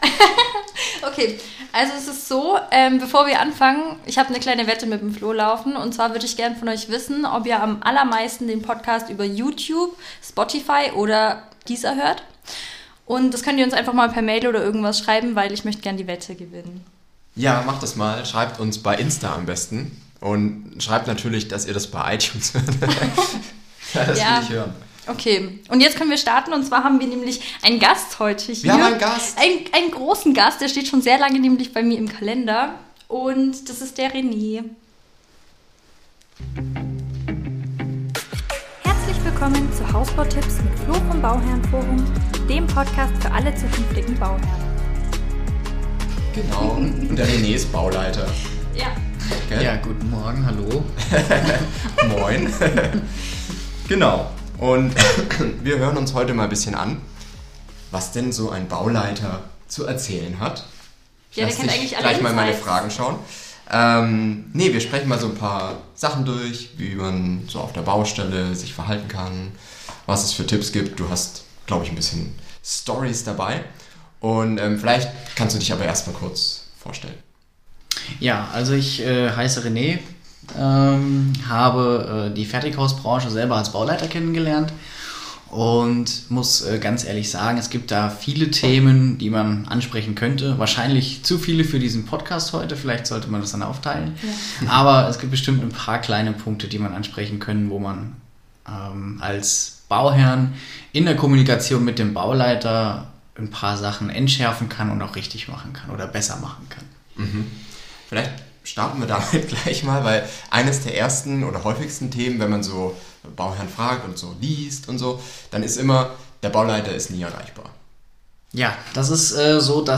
Heute gibt`s die erste Interview Folge :-)